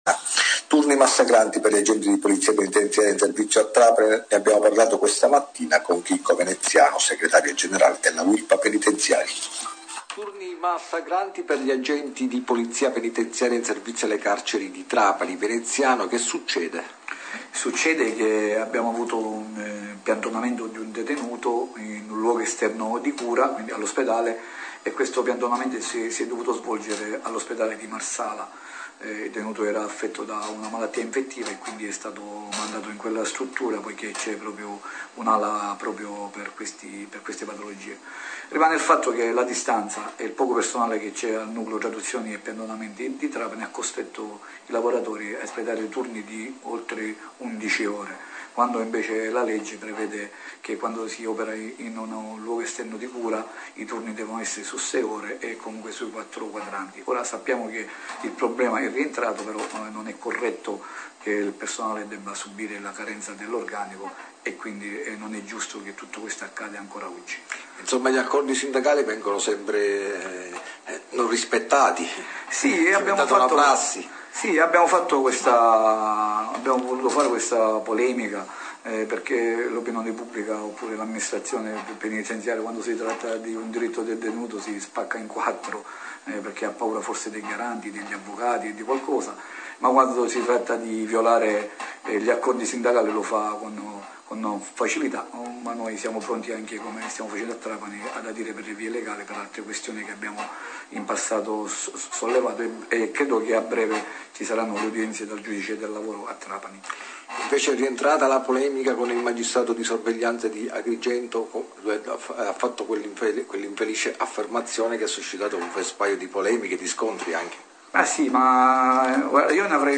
...INTERVISTA